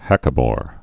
(hăkə-môr)